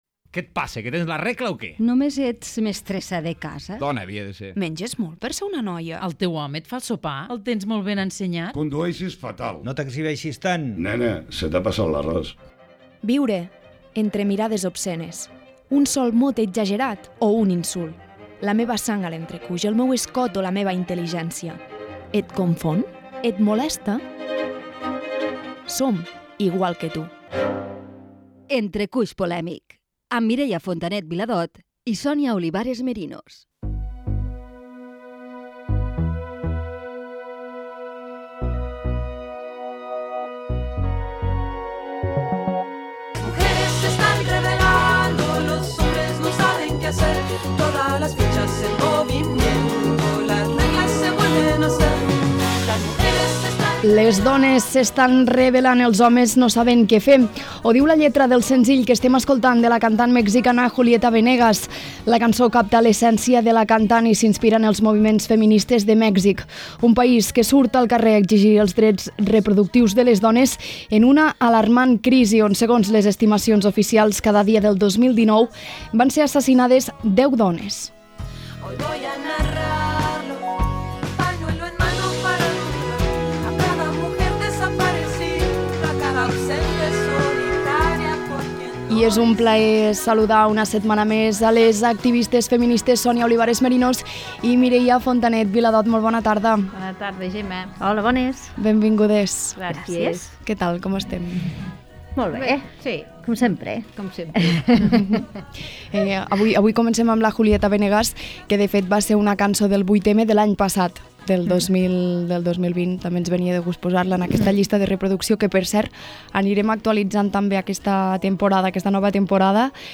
Careta de la secció "Entrecuix polèmic", dada de les dones assassinades a Mèxic, dones que es van fer passar per homes per poder fer el que volien Gènere radiofònic Info-entreteniment